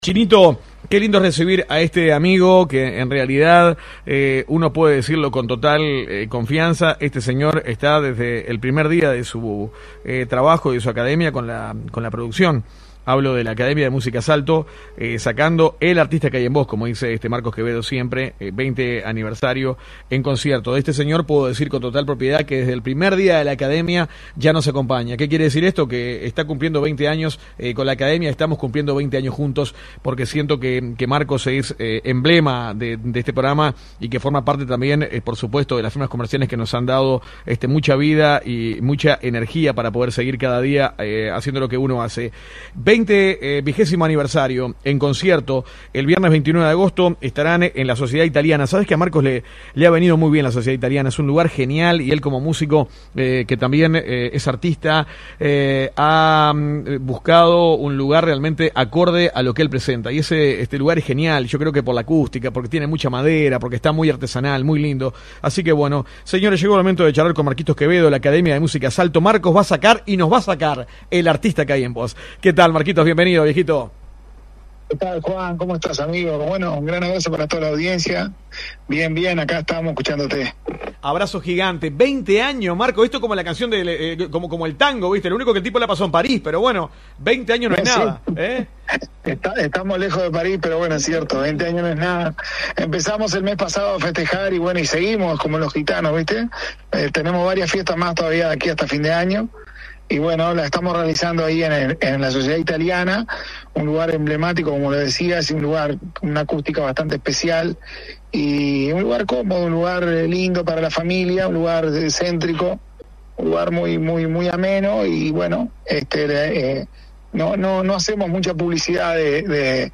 Comunicación telefónica